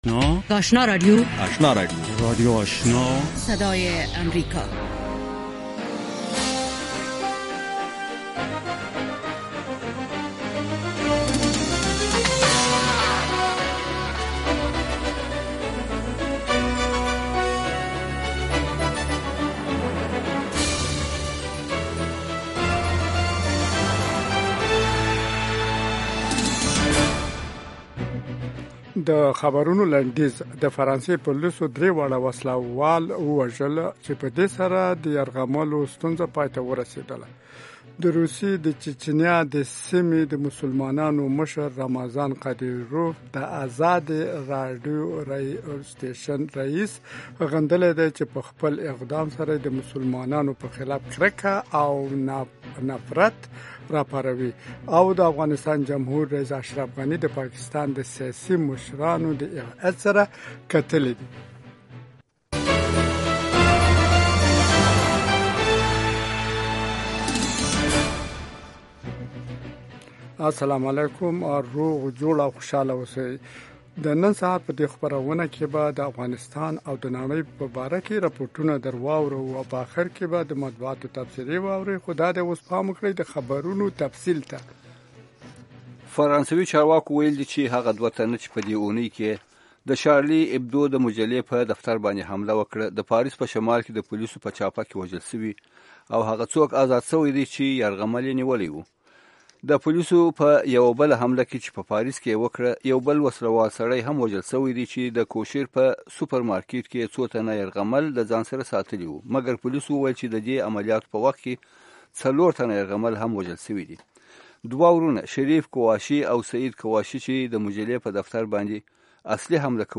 دویمه سهارنۍ خبري خپرونه
په دې نیم ساعته خپرونه کې د افغانستان او نورې نړۍ له تازه خبرونو وروسته مهم رپوټونه او مرکې اورېدای شئ.